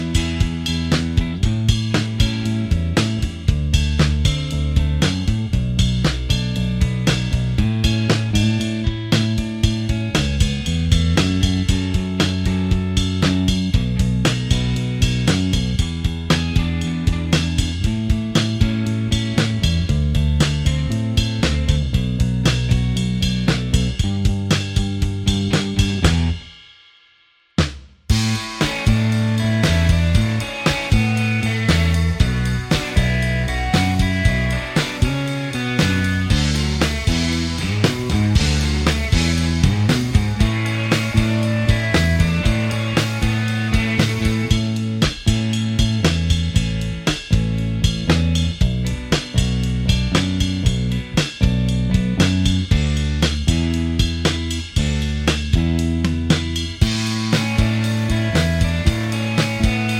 Minus Main Guitar For Guitarists 3:01 Buy £1.50